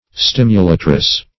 Stimulatress \Stim"u*la`tress\, n. A woman who stimulates.